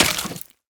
25w18a / assets / minecraft / sounds / mob / bogged / hurt4.ogg
hurt4.ogg